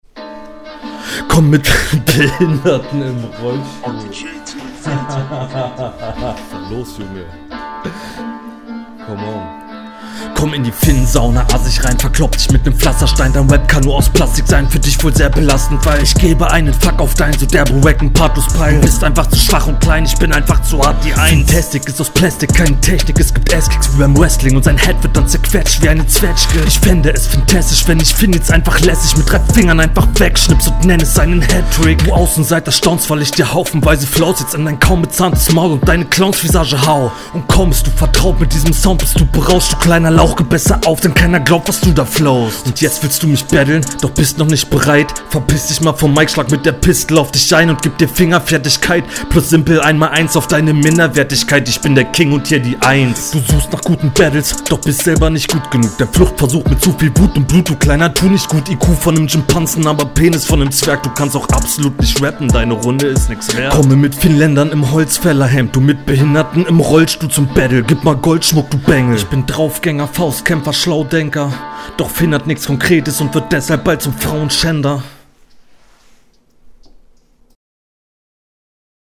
bist gut on point. du hast so einen agressiven stimmeinsatz und es klingt trotzdem bisi …